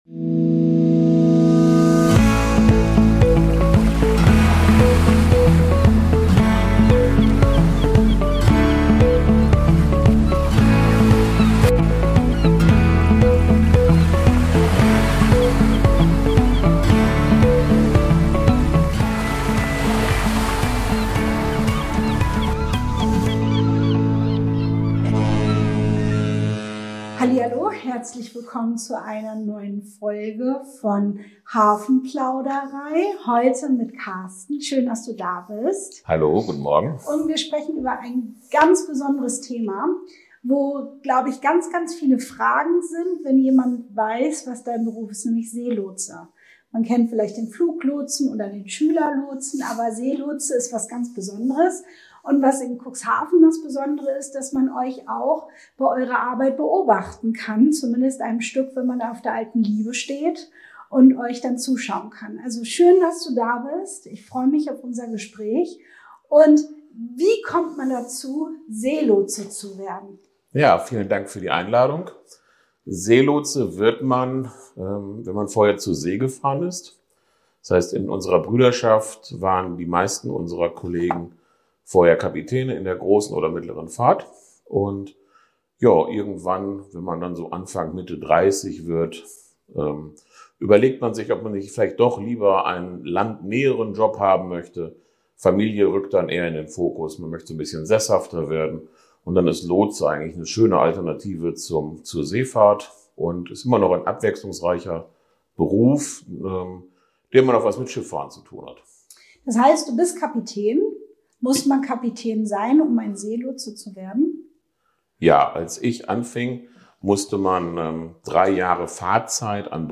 Wie kommen riesige Schiffe sicher durch die Elbmündung? In dieser Folge der Hafenplauderei spricht ein Lotse aus Cuxhaven über seinen spannenden Alltag zwischen Nordsee, Elbe und internationaler Schifffahrt.